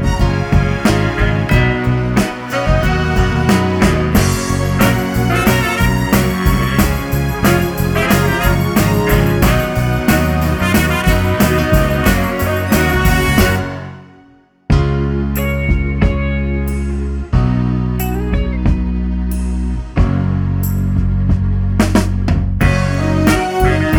no Backing Vocals Jazz / Swing 3:15 Buy £1.50